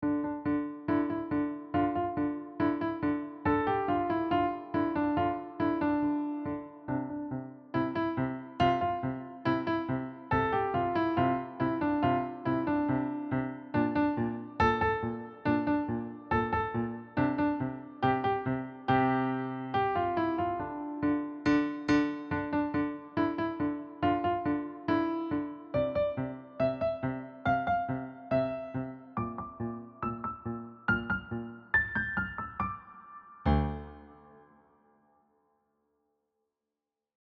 Key: D minor
Time signature: 4/4
Character: Fast, intense, dramatic